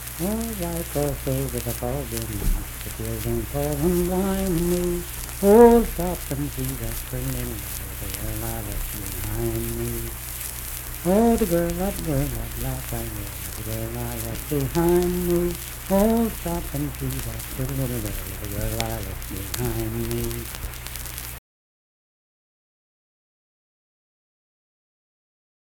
Unaccompanied vocal music performance
Verse-refrain 2(4w/R).
Voice (sung)
Sutton (W. Va.), Braxton County (W. Va.)